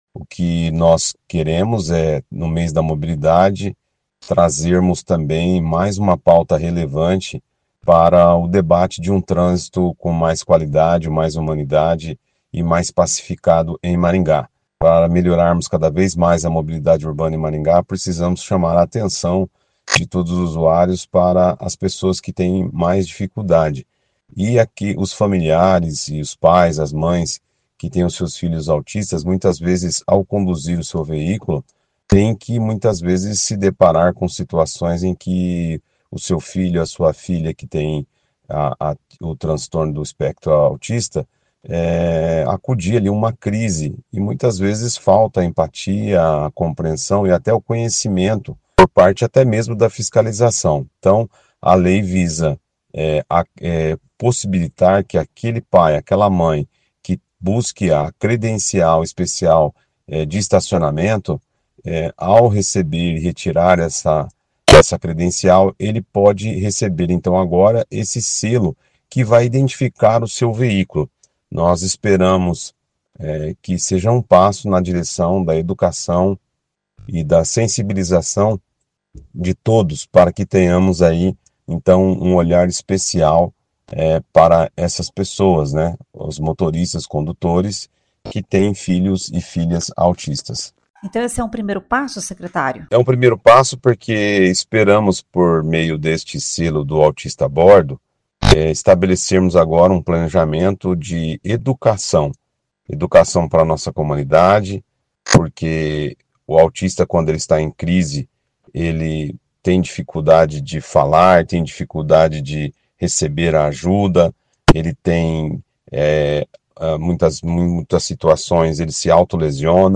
Ouça o que diz o secretário: